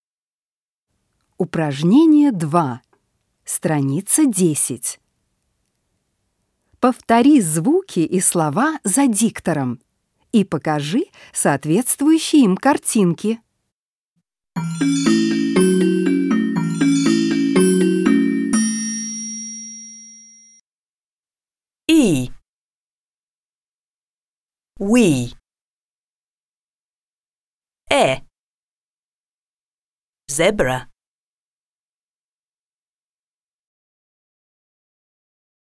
2. Повтори звуки и слова за диктором и покажи соответствующие им картинки.